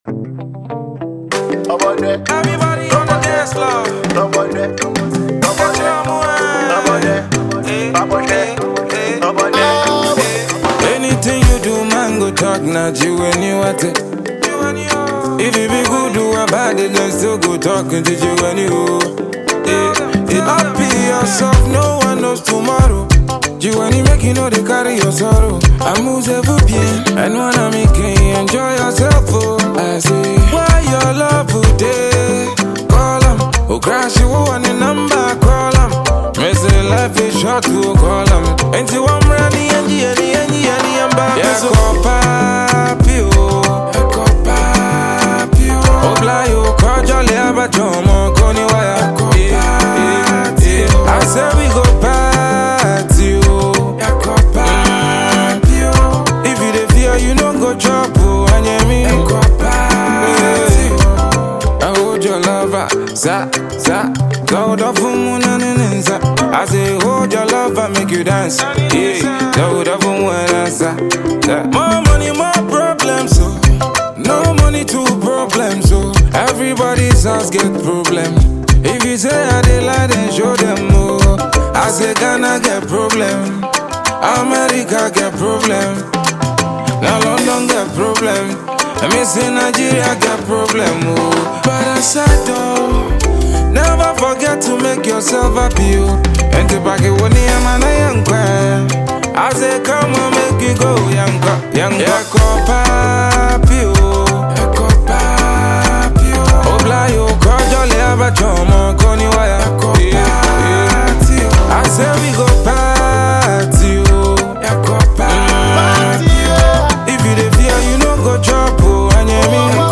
Ghanaian highlife